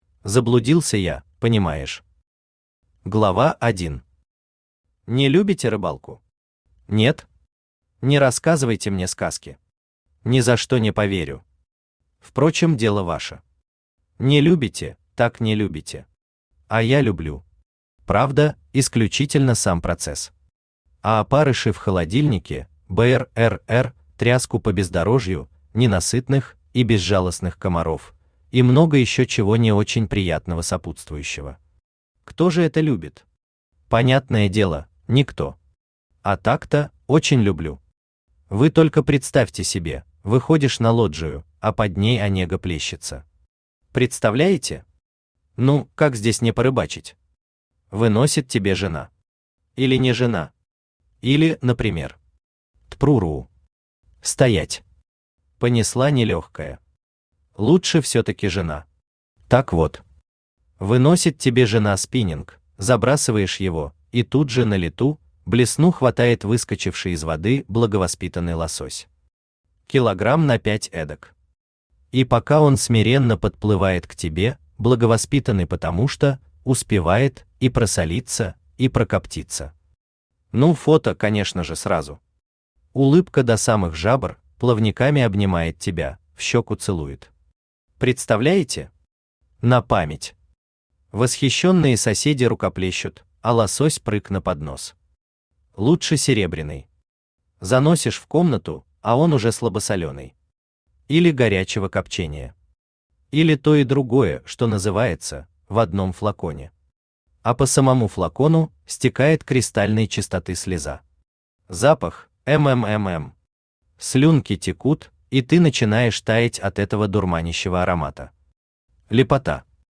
Аудиокнига Заблудился я, понимаешь…
Автор Сергей Марксович Бичуцкий Читает аудиокнигу Авточтец ЛитРес.